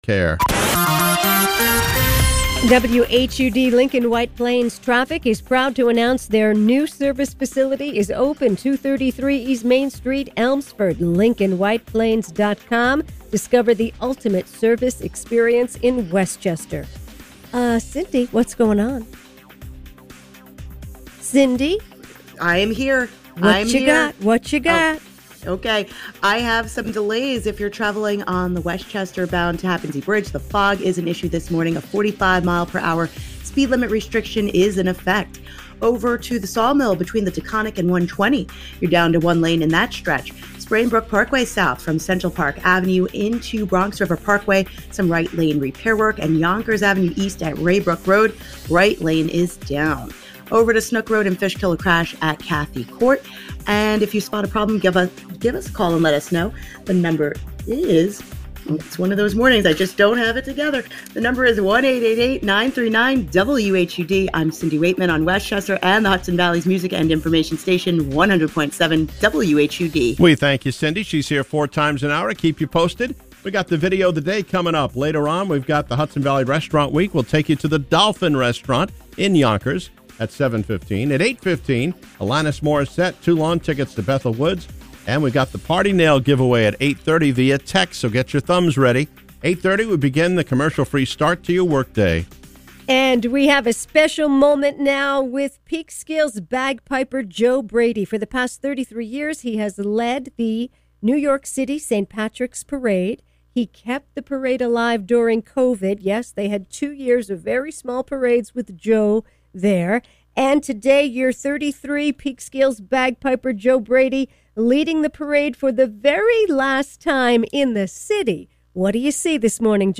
bagpiper